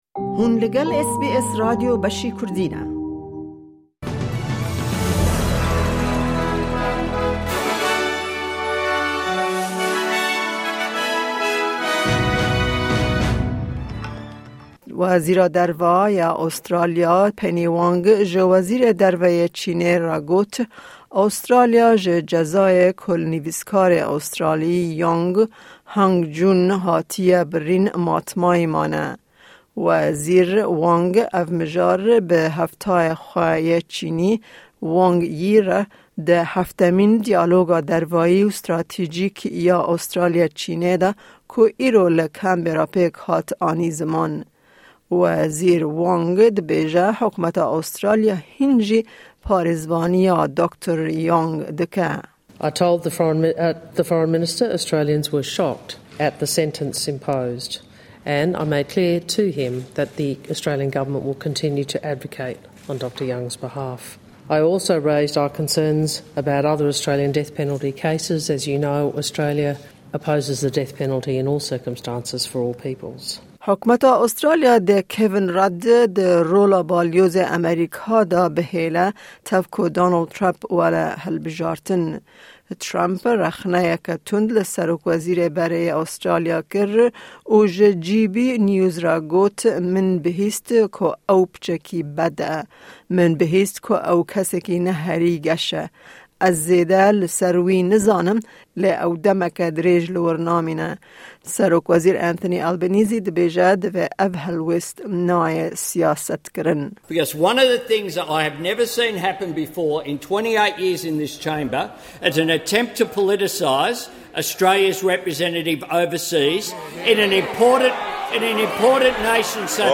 Kurte Nûçeyên roja Çarşemê 20î Adara 2024